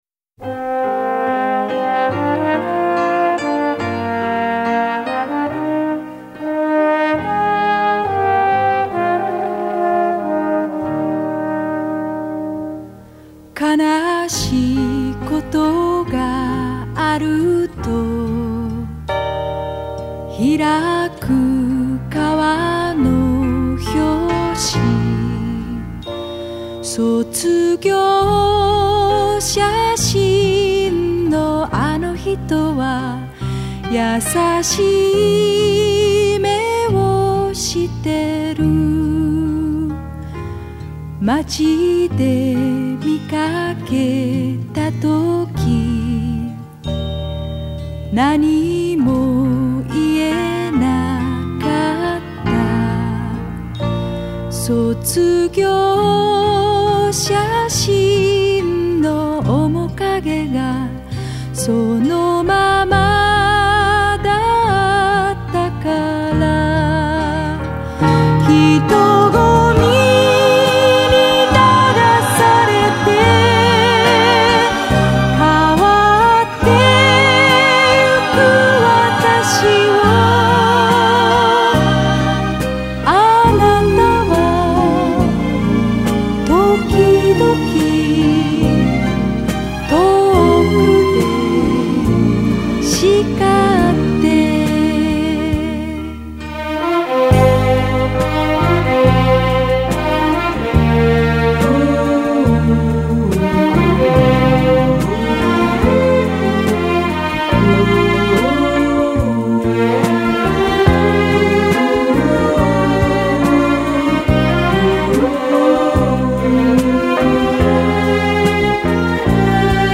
音源: うちのレコード